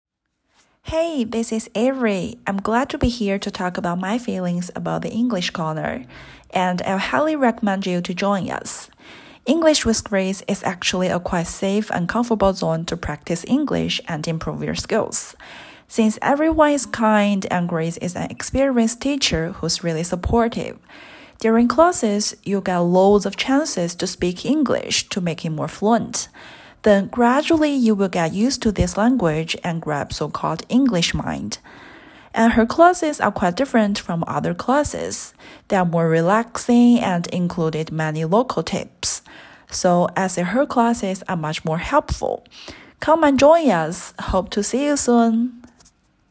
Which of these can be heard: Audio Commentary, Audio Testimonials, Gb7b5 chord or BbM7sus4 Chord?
Audio Testimonials